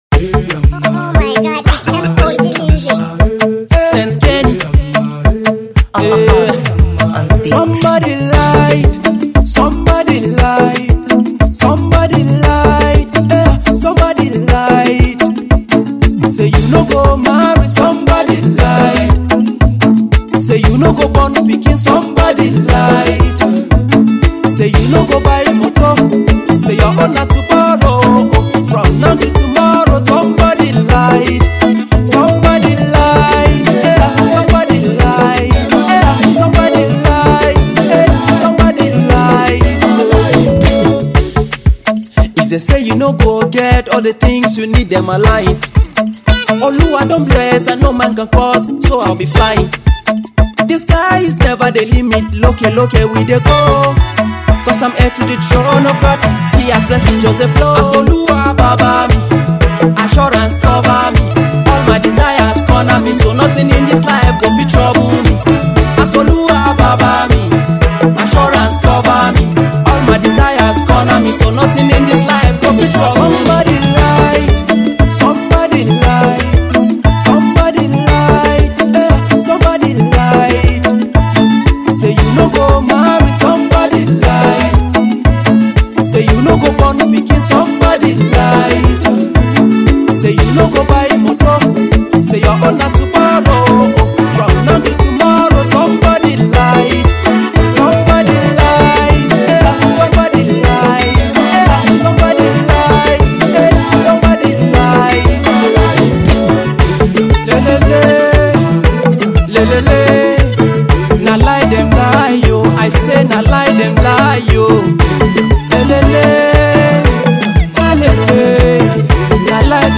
groovy song